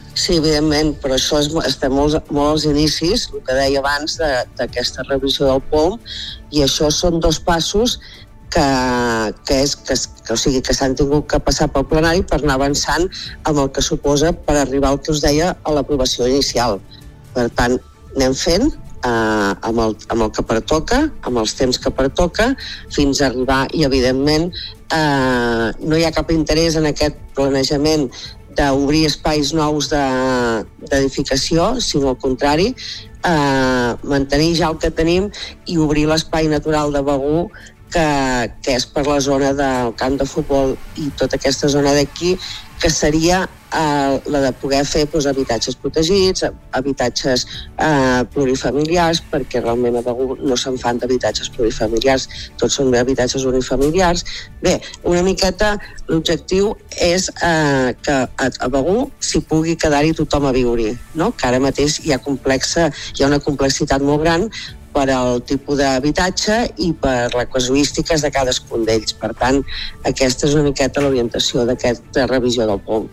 Supermatí - entrevistes
I per parlar de la organització d’aquest POUM i d’aquesta aprovació hem parlat al Supermatí amb l’alcaldessa de Begur i Esclanyà, Maite Selva.